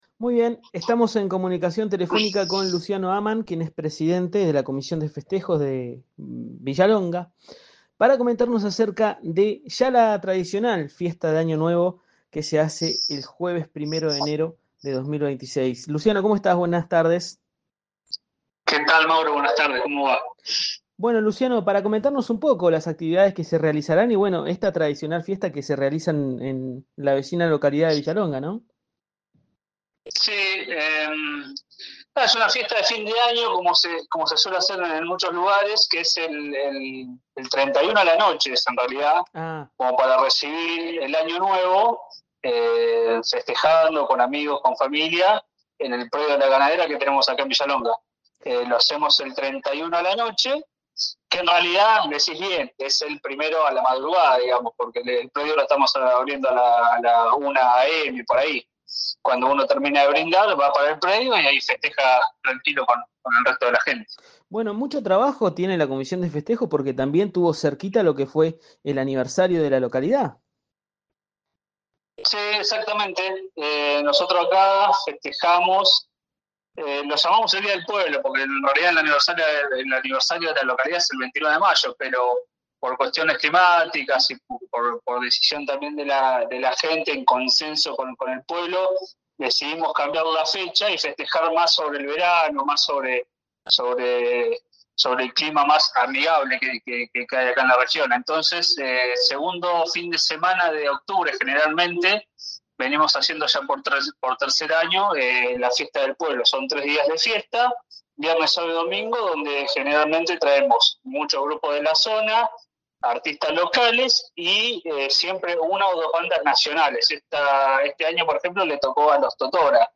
En comunicación telefónica